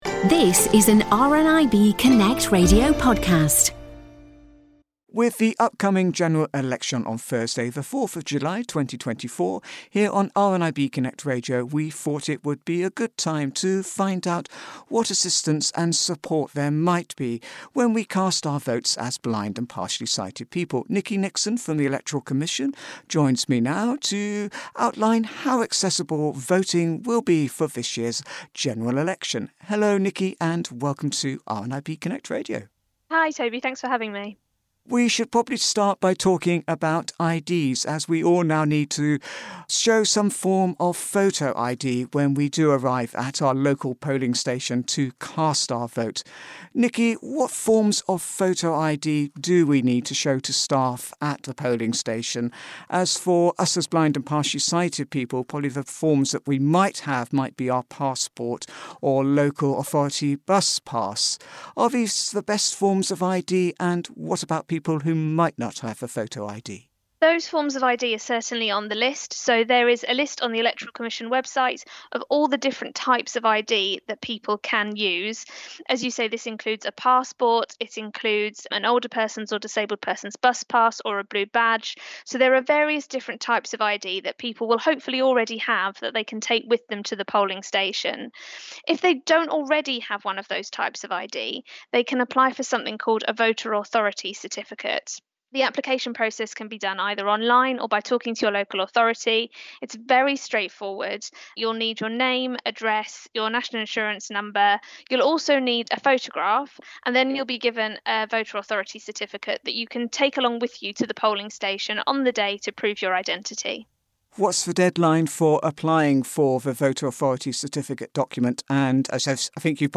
The deadlines mentioned in this interview have now passed, if you have registered to vote, remember to bring a photo ID or your Voter Authority Certificate if voting in England, Scotland, and Wales, or your Electoral ID Card if voting in Northern Ireland.